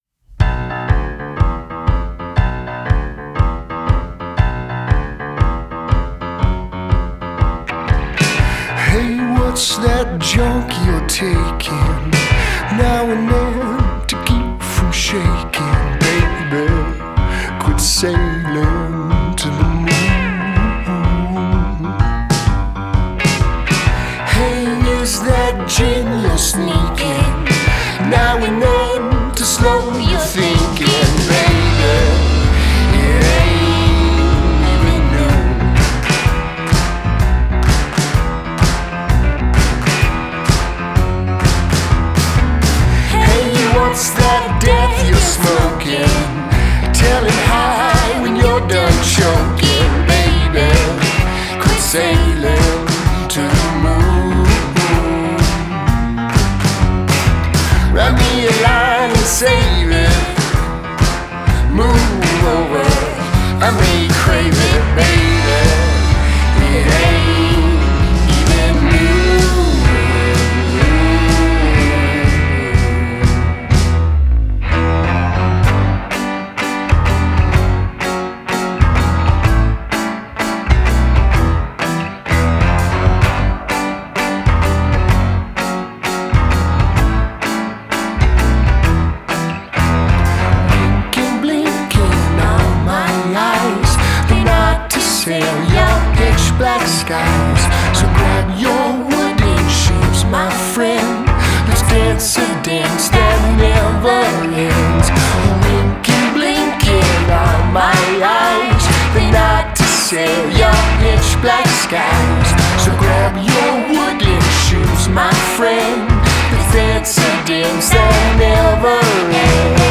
Genre: Indie Rock / Folk